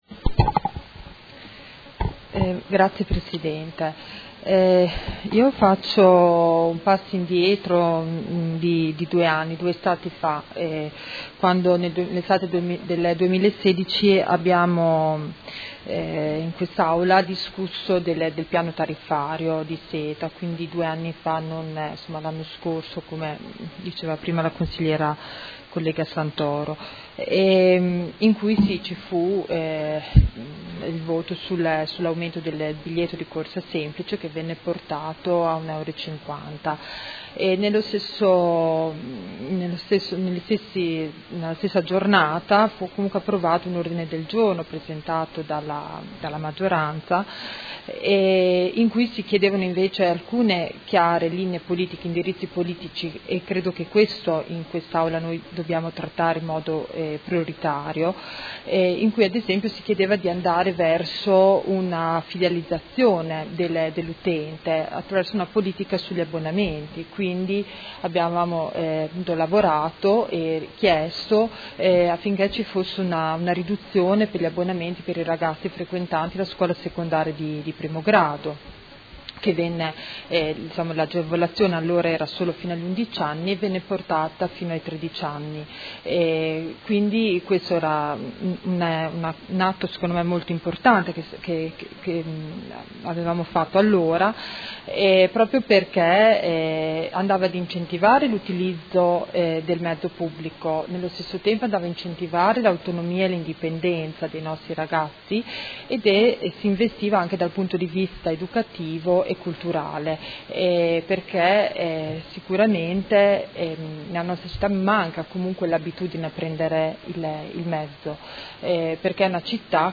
Seduta del 21/06/2018 Dibattito. Delibera nr. 84798 Convenzione tra i Soci pubblici modenesi di SETA S.p.A. - Approvazione